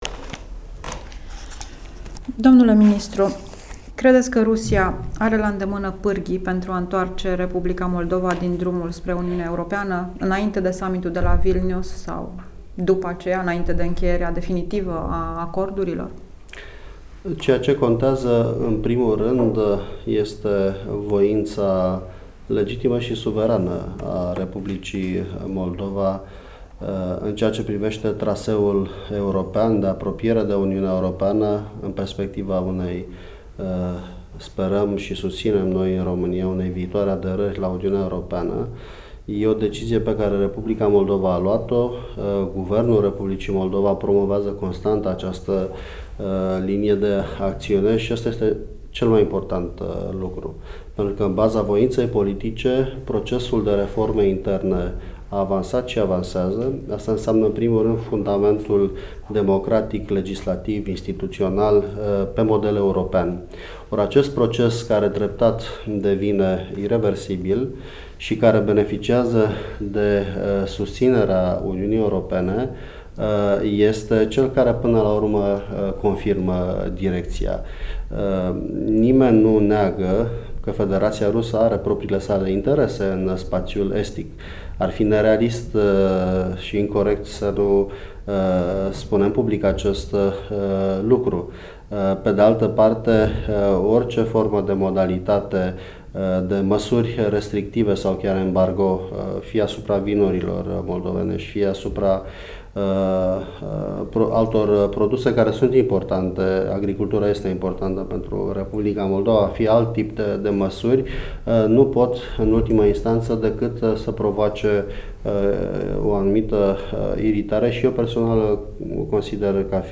Interviul acordat Europei Libere de Titus Corlățean, ministrul de externe al României